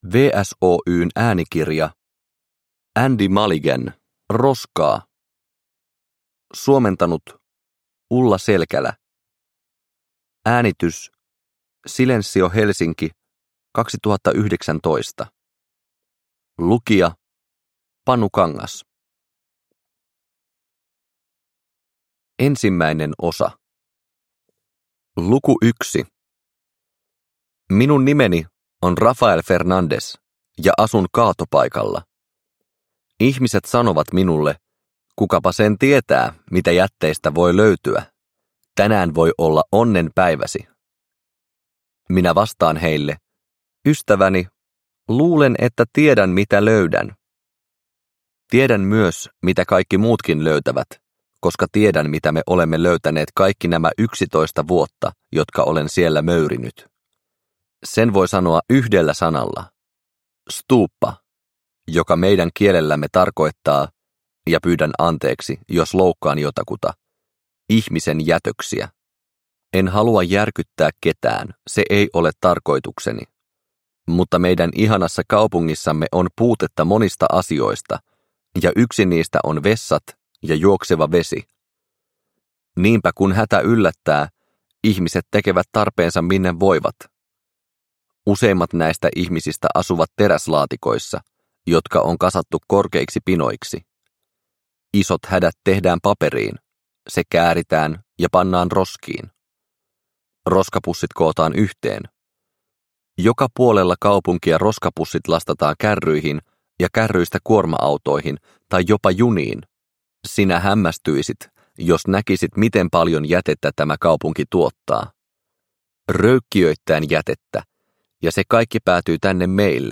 Roskaa – Ljudbok – Laddas ner